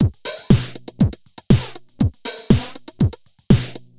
Beat 05 Full (120BPM).wav